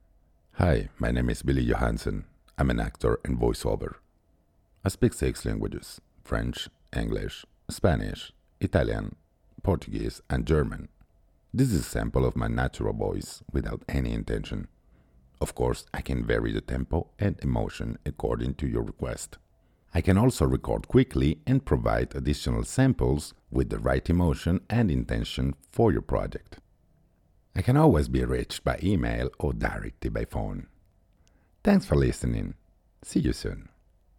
Voix off
40 - 80 ans - Baryton-basse